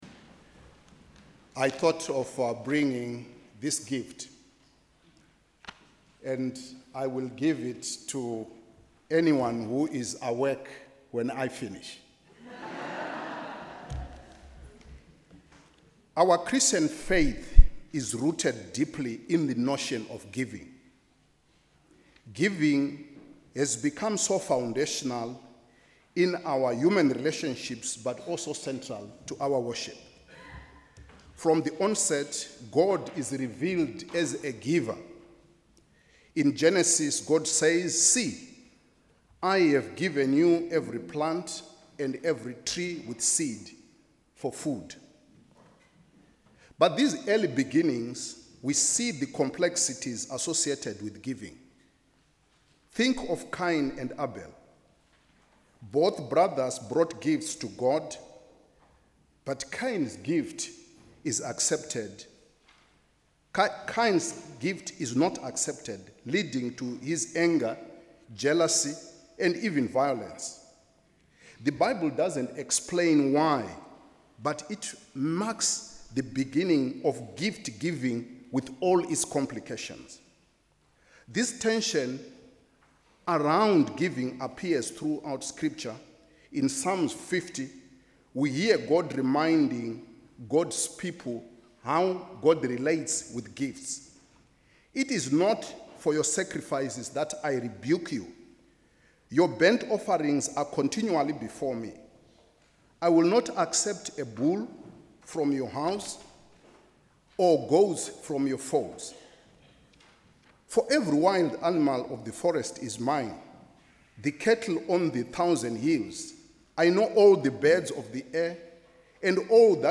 Sermon: ‘The grace of giving’